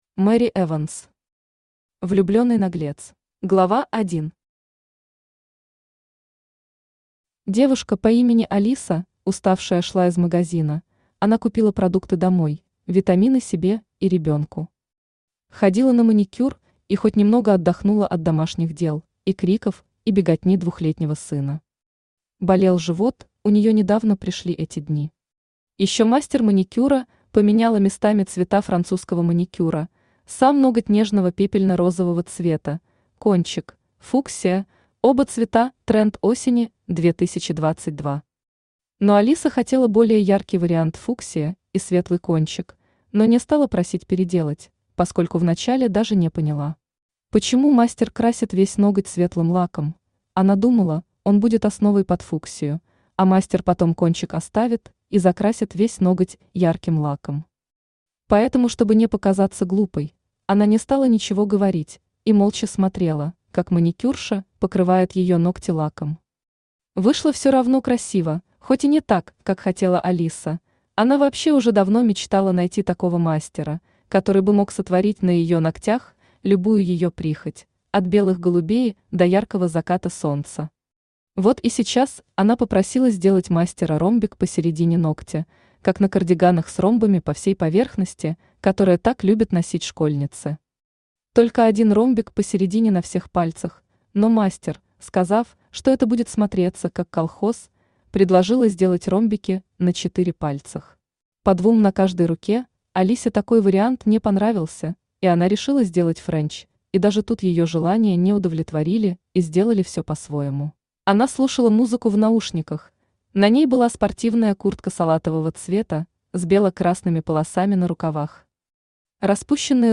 Аудиокнига Влюбленный наглец | Библиотека аудиокниг
Aудиокнига Влюбленный наглец Автор Мэри Эванс Читает аудиокнигу Авточтец ЛитРес.